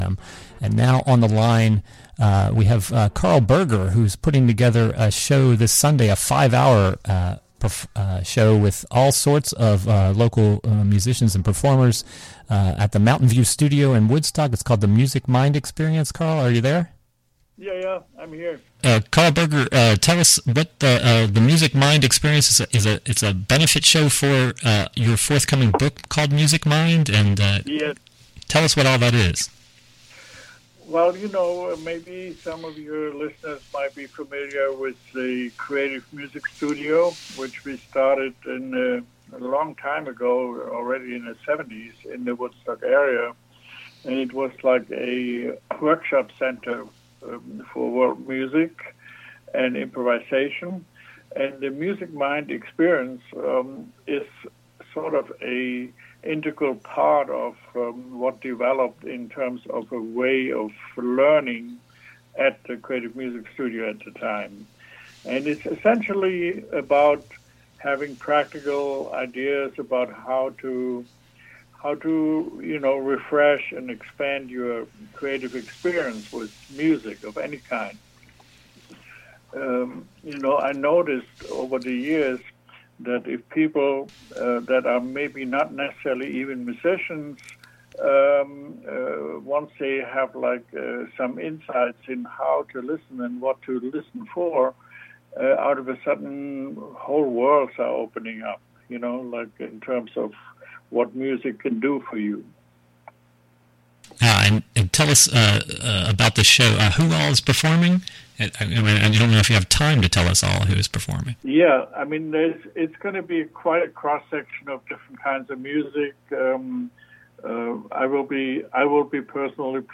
Karl Berger calls in to preview his Music Mind Exp...